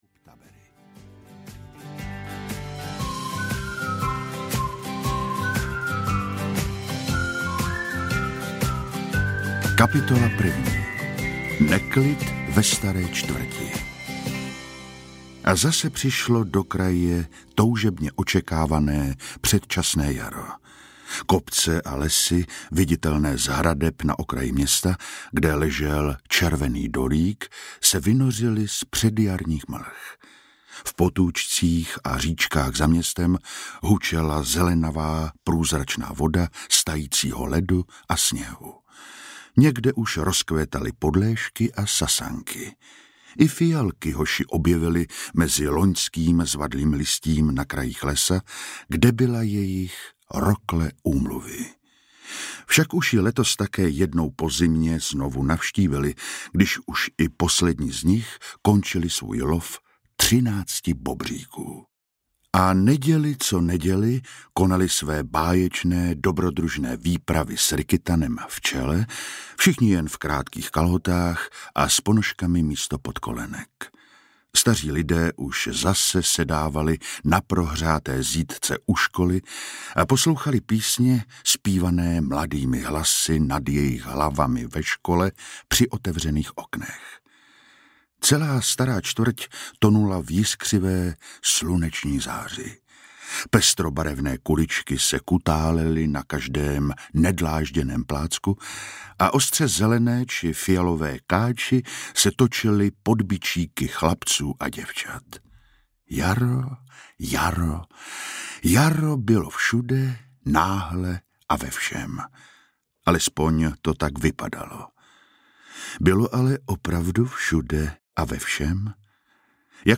Strach nad Bobří řekou audiokniha
Ukázka z knihy